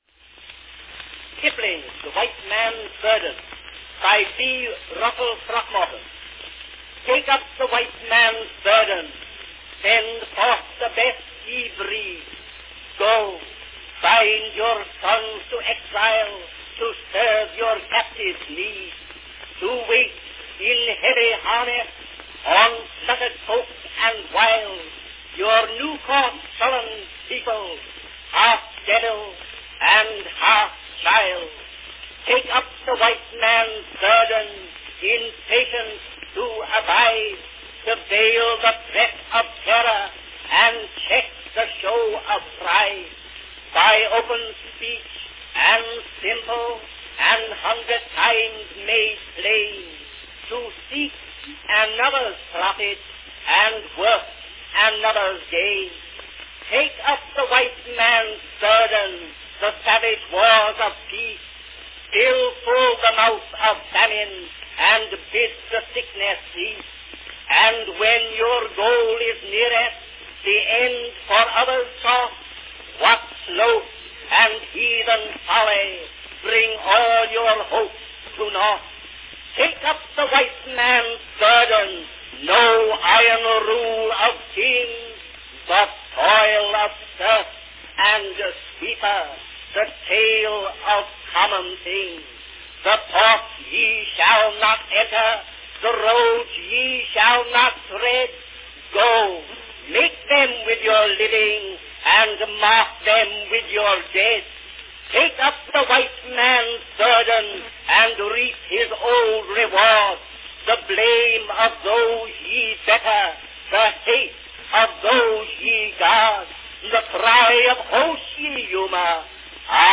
From 1899, an early recitation of Rudyard Kipling's challenge to Western minds and powers in his poem The White Man’s Burden.
Company Edison's National Phonograph Company
Category Recitation
A very fine recording of Kipling's thought-provoking poem of exhortation and caution on the march of Western civilization and progress.